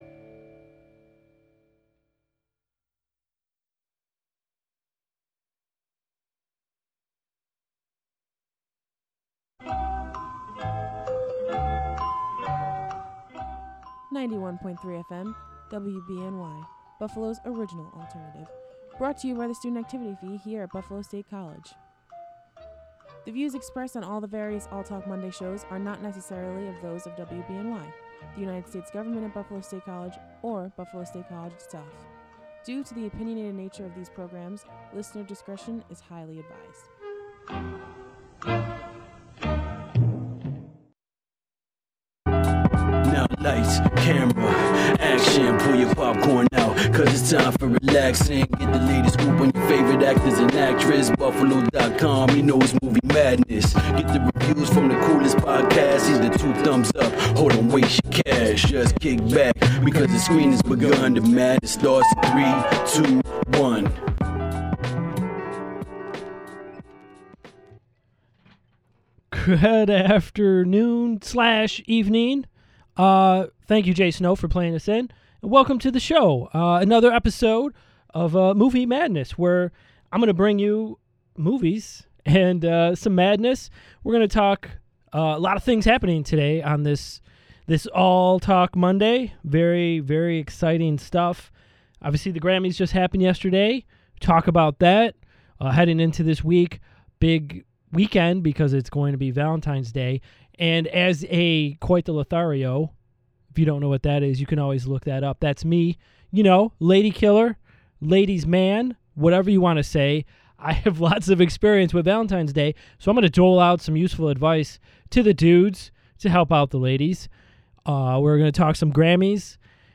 comedy interview